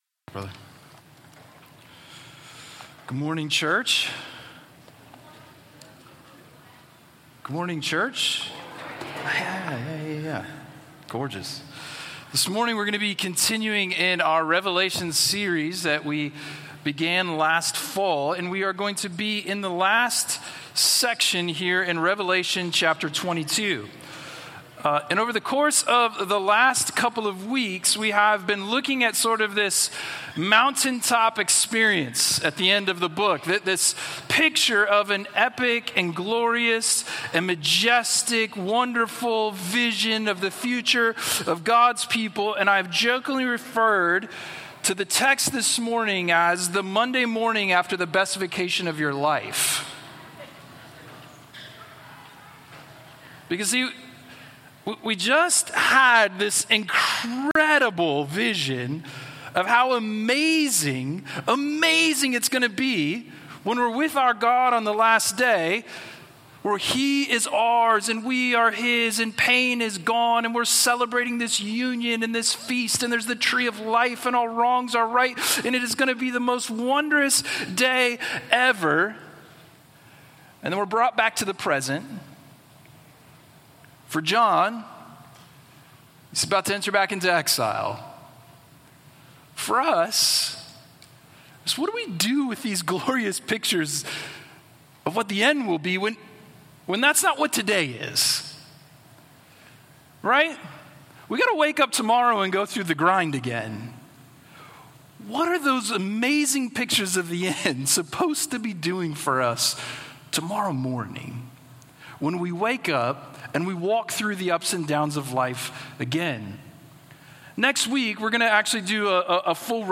Sunday Sermons – Crossway Community Church